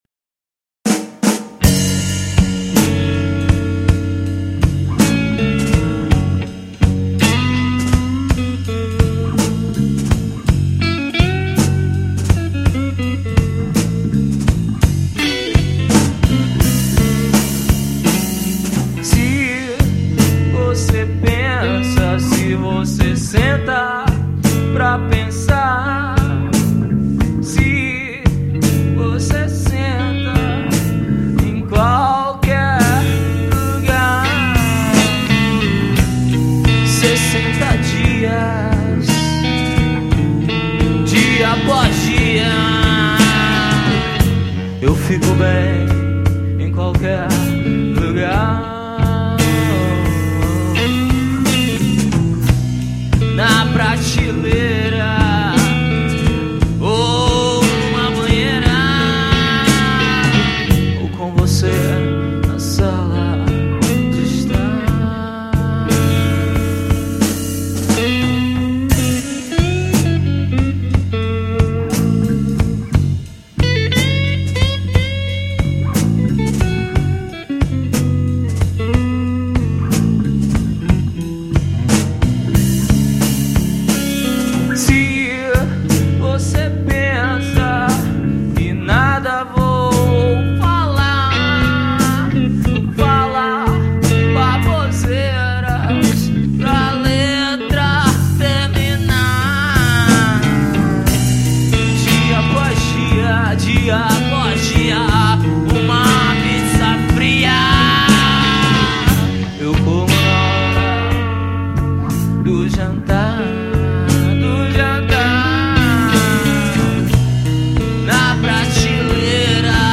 1715   06:30:00   Faixa:     Rock Nacional